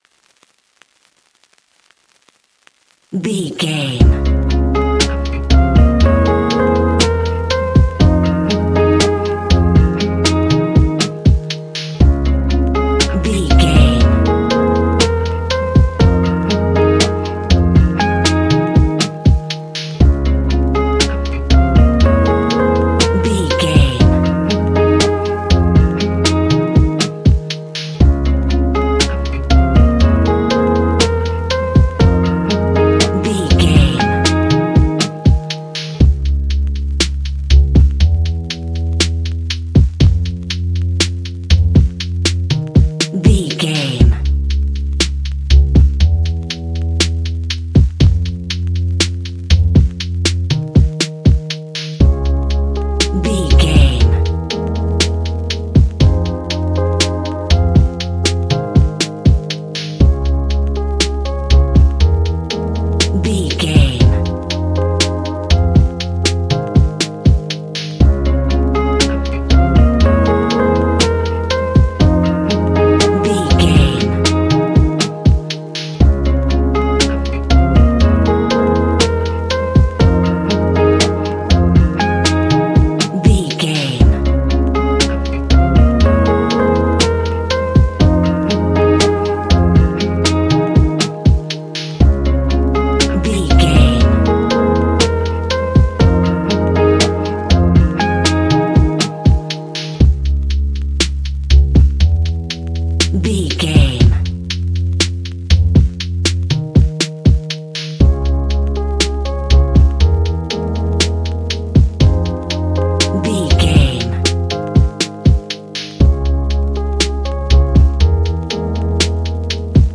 Ionian/Major
F♯
chilled
laid back
sparse
new age
chilled electronica
ambient
atmospheric